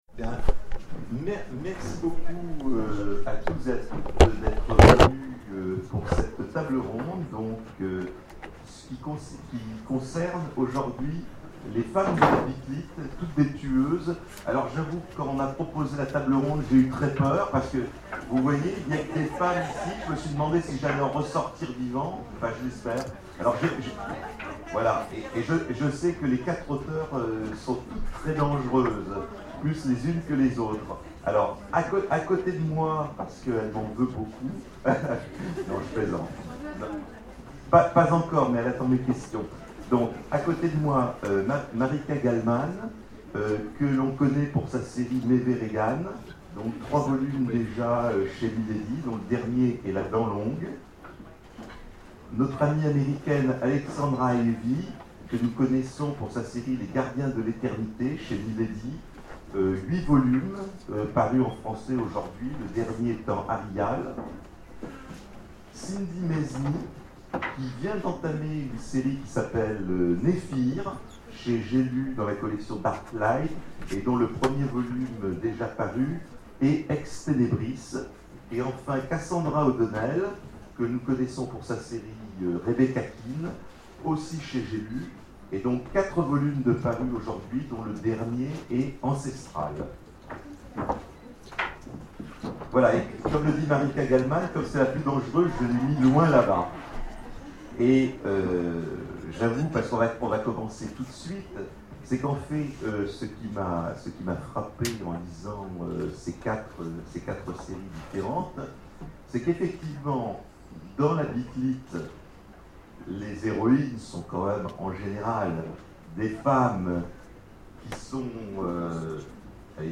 Imaginales 2013 : Conférence Les femmes de la bit-lit...